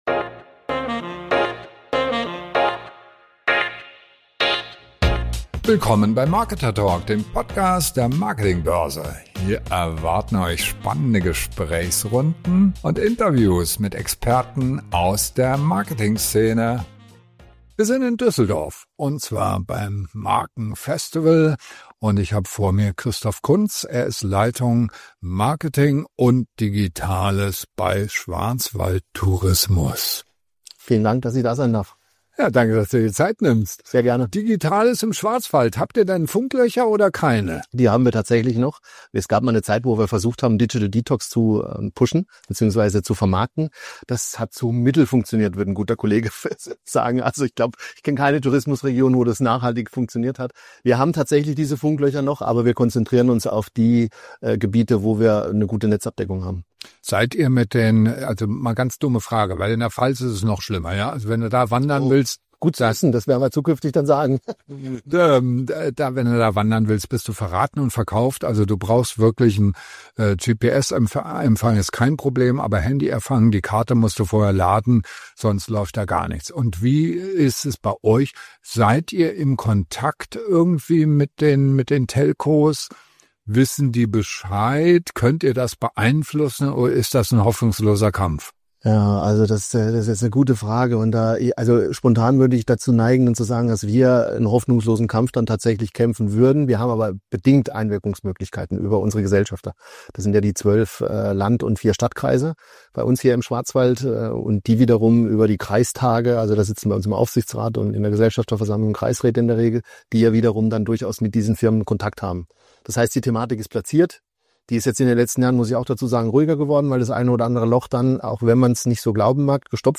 Digitalisierung, Markenaufbau und die richtige Pressearbeit: Ein Gespräch über das, was Destinationen wirklich voranbringt. Weniger Klischees, mehr authentische Strategien.